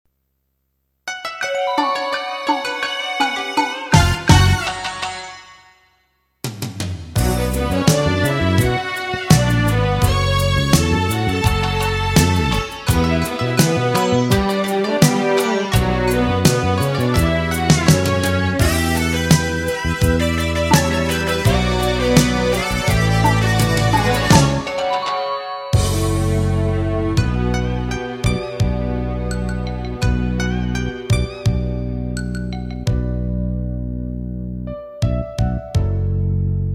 " a Japanese Enka song.
Gear: Yamaha MU100, Yamaha SY99 (controller)